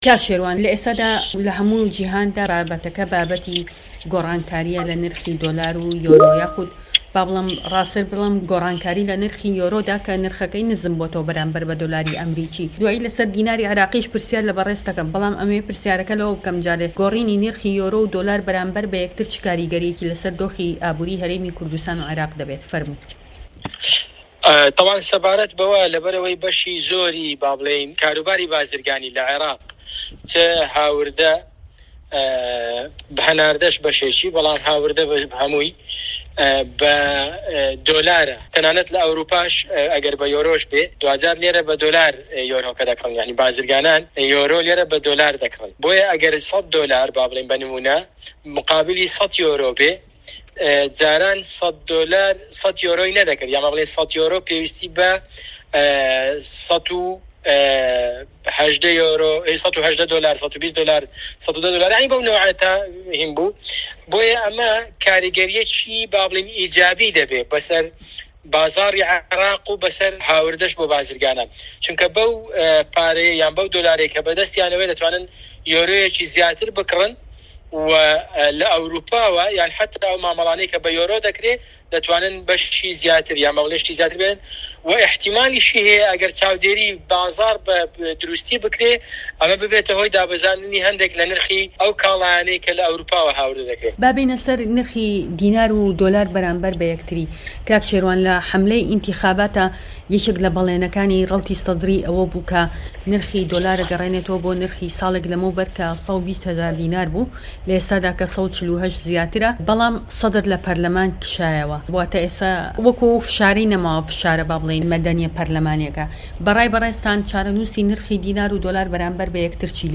شێروان میرزا ئەندامی لیژنەی کاروباری دارایی و ئابووری لەپەرلەمانی عێڕاق
دەقی قسەکانی شێروان میرزا لەم وتووێژەدا